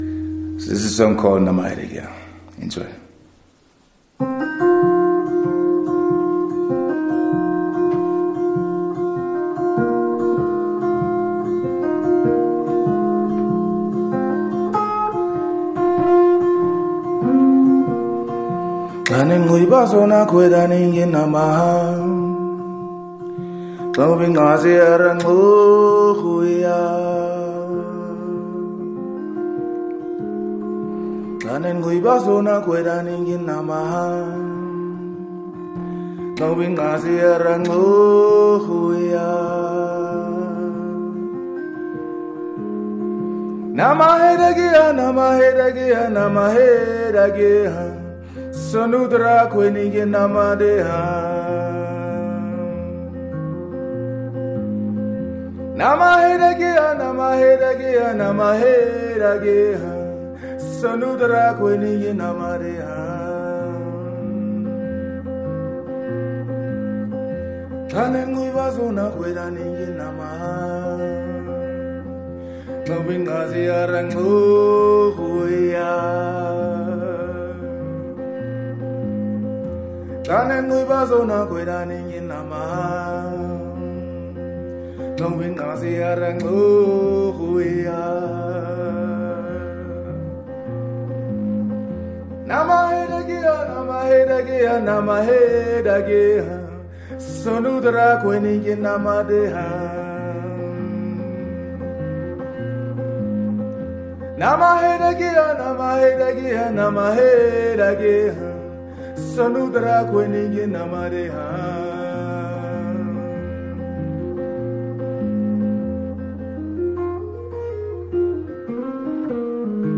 Live
joins us live in studio playing his song